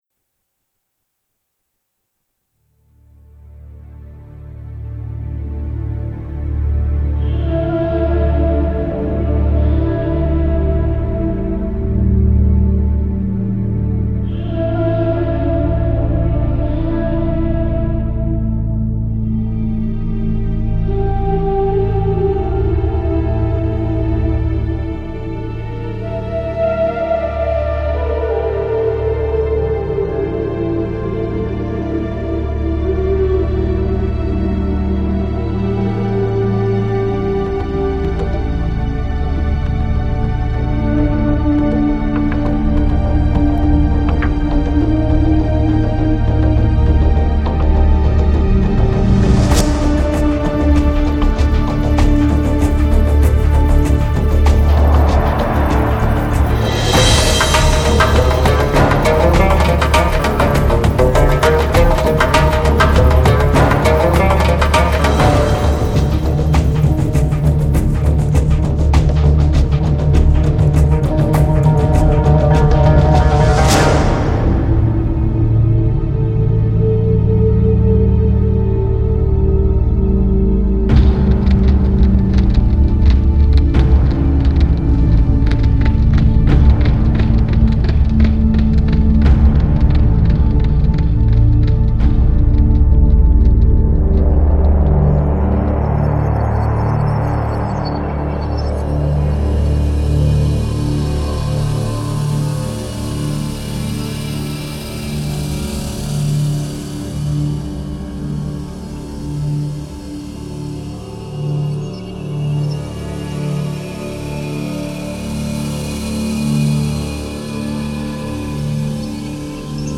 Film Trailer/World: Middle Eastern